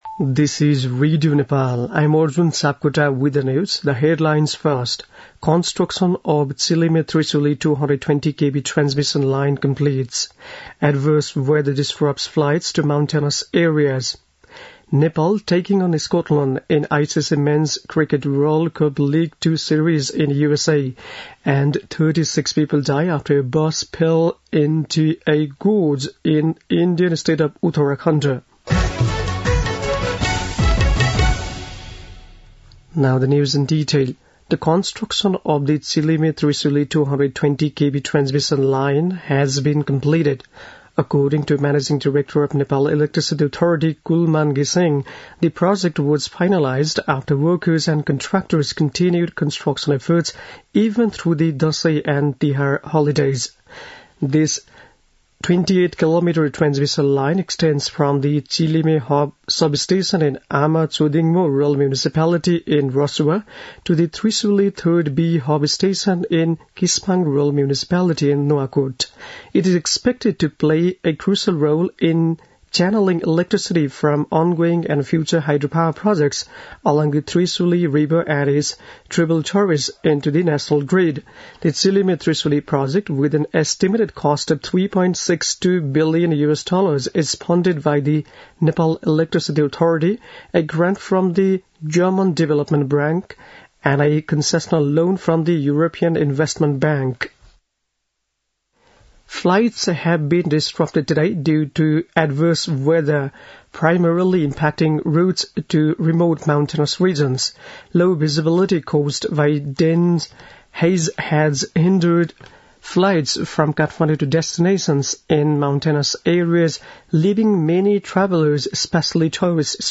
बेलुकी ८ बजेको अङ्ग्रेजी समाचार : २० कार्तिक , २०८१